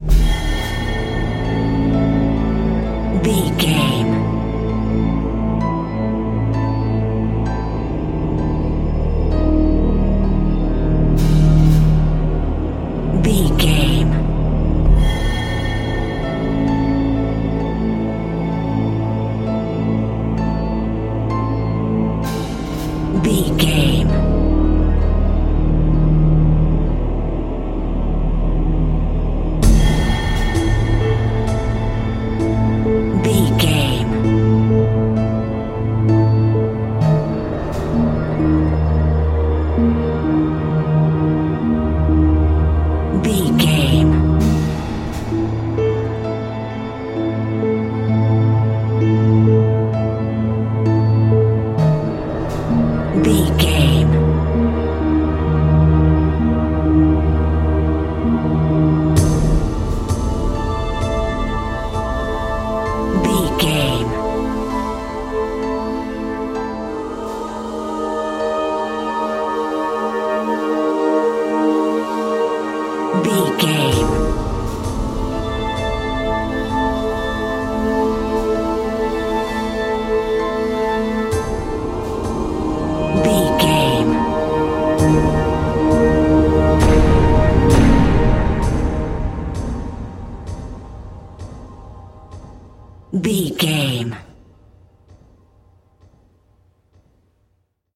Aeolian/Minor
synthesiser
strings
percussion
tension
ominous
dark
suspense
haunting
creepy
spooky